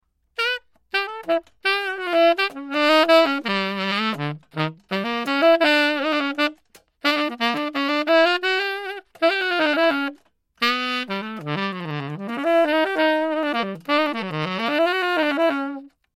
É un saxofón tenor, e o seu rexistro ven sendo bastante grave se o comparamos co resto de membros da súa familia.
saxofon.mp3